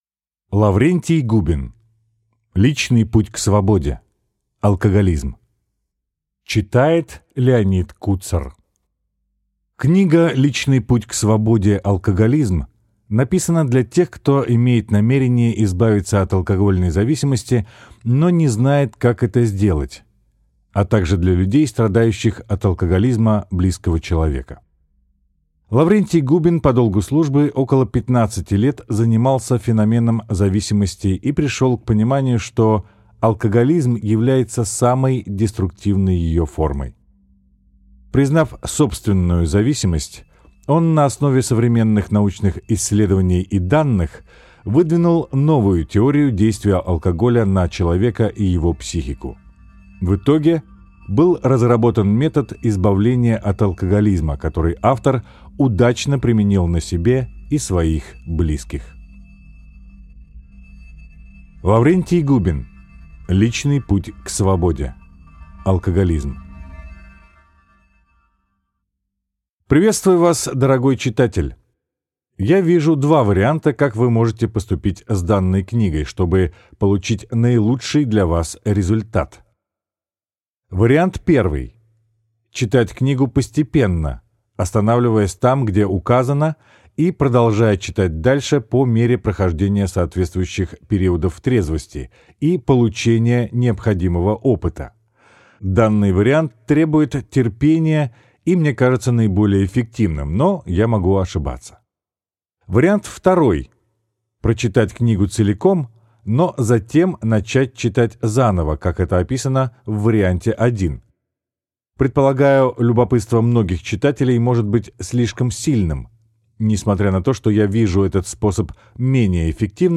Аудиокнига Личный путь к свободе. Алкоголизм | Библиотека аудиокниг